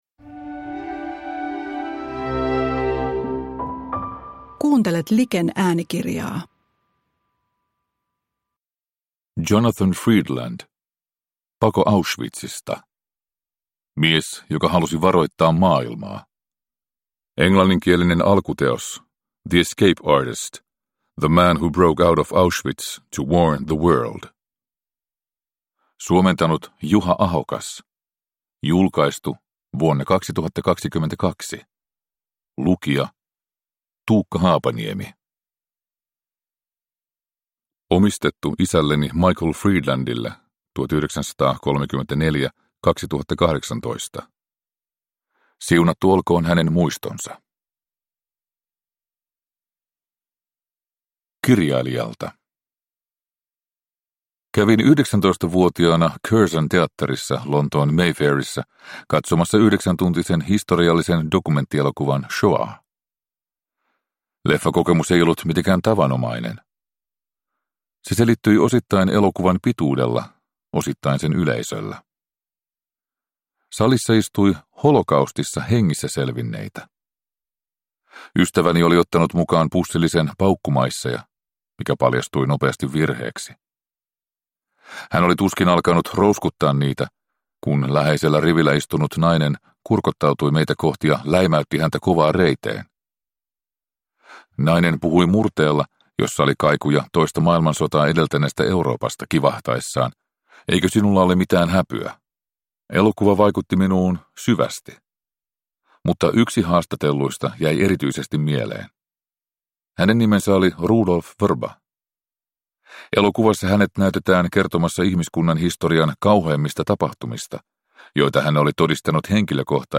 Pako Auschwitzista – Ljudbok – Laddas ner